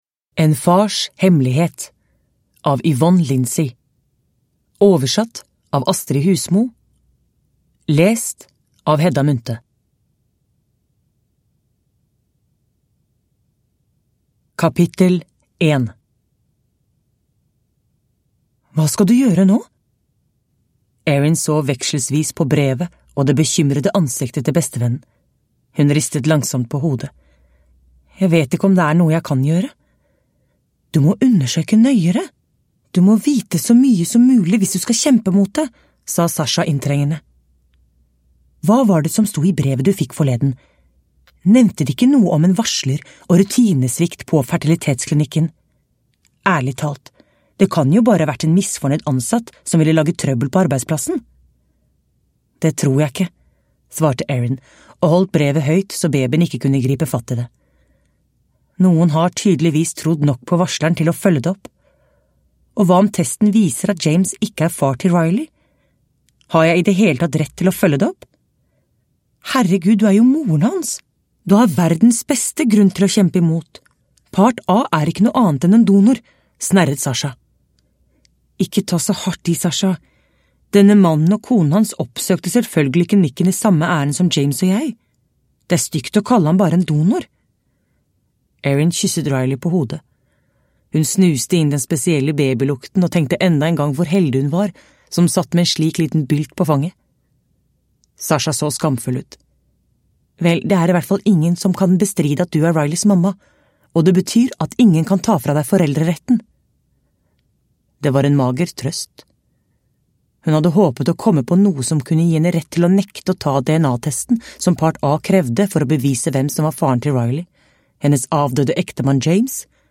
En fars hemmelighet – Ljudbok – Laddas ner